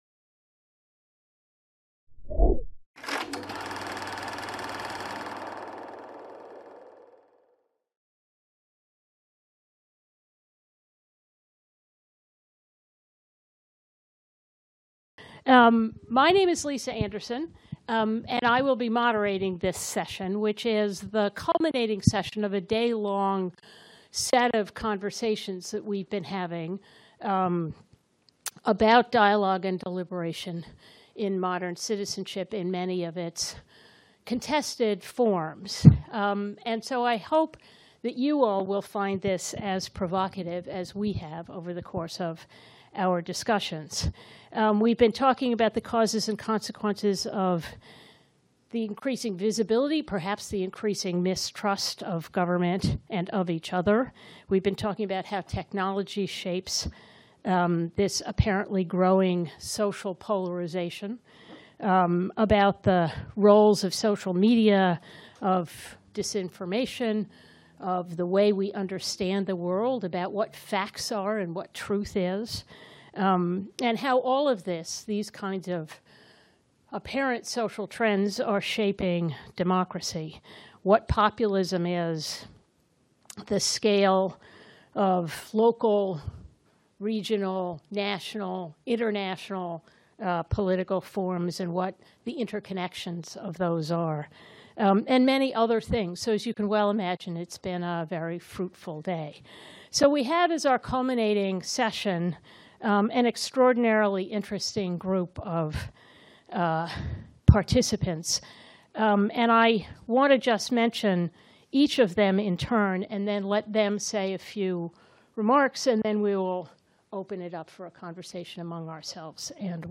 Roundtable Restoring dialogue and deliberation in contemporary society | Canal U
Roundtable organized by the FMSH, Reset Dialogues on civilizations and the Calouste Gulbenkian Foundation’s Delegation in France as part of the one-day conference The Fragility of Democracy: Rescuing Dialogue and Deliberation.